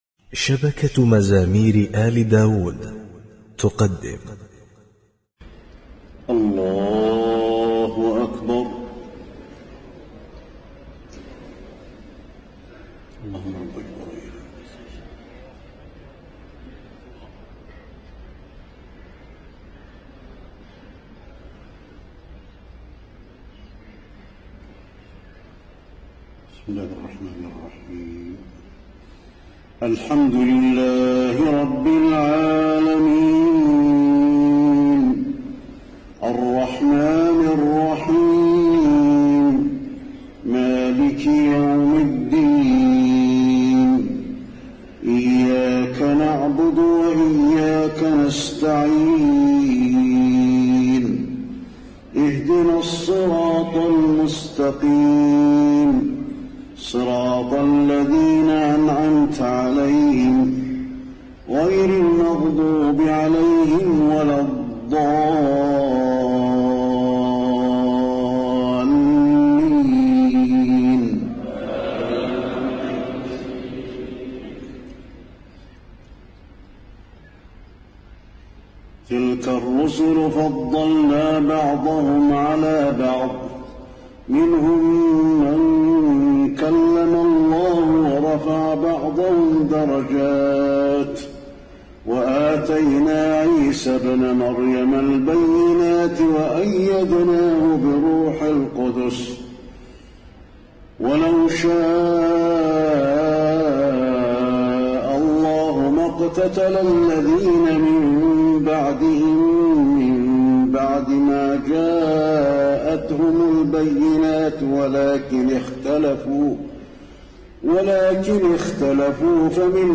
تهجد ليلة 22 رمضان 1432هـ من سورتي البقرة (253-286) و آل عمران (1-17) Tahajjud 22 st night Ramadan 1432H from Surah Al-Baqara and Aal-i-Imraan > تراويح الحرم النبوي عام 1432 🕌 > التراويح - تلاوات الحرمين